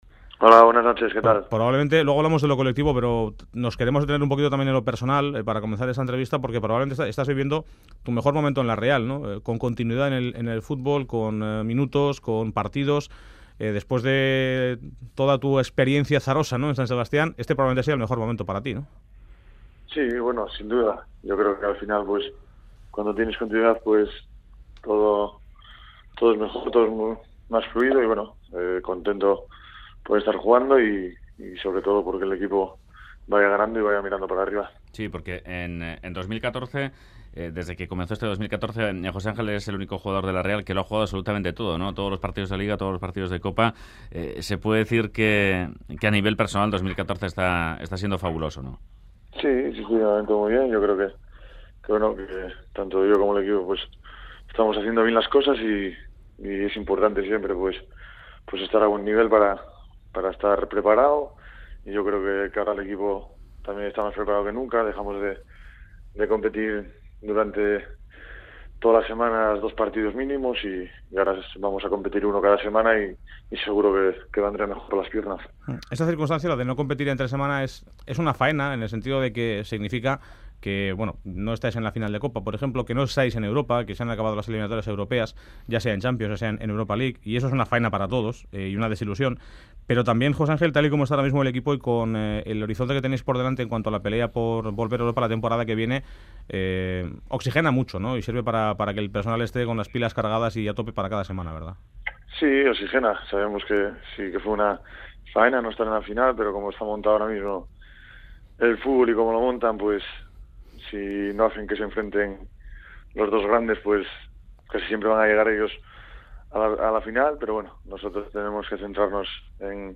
Fuera de juego recibe al jugador de la Real JOSE ANGEL VALDES , “COTE”. El asturiano disfruta ahora de su mejor momento como futbolista txuri urdin.